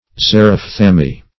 Xerophthalmy \Xe`roph*thal"my\, n.